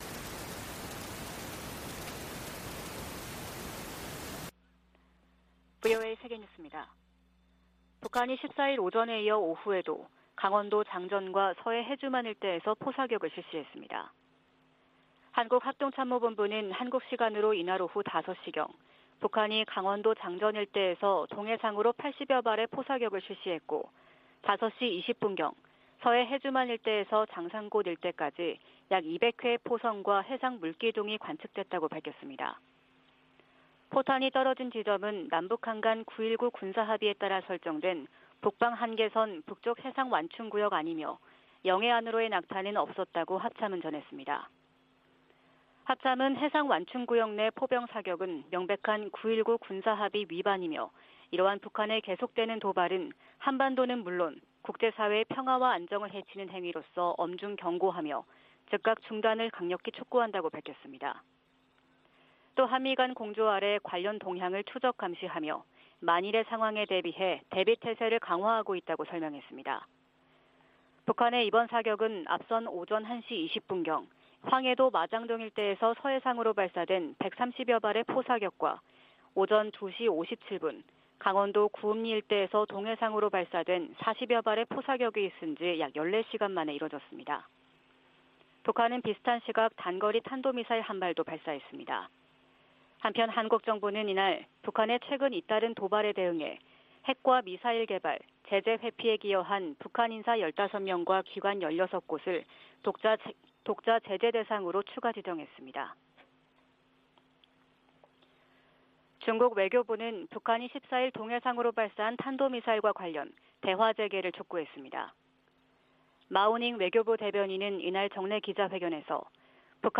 VOA 한국어 '출발 뉴스 쇼', 2022년 10월 15일 방송입니다. 북한이 포 사격을 포함해 군용기 위협 비행, 탄도미사일 발사 등 무차별 심야 도발을 벌였습니다. 한국 정부가 북한의 노골화되는 전술핵 위협에 대응해, 5년 만에 대북 독자 제재에 나섰습니다. 미국이 로널드 레이건 항모강습단을 동원해 한국·일본과 실시한 연합훈련은 역내 안정 도전 세력에 대한 단합된 결의를 보여준다고 미 7함대가 밝혔습니다.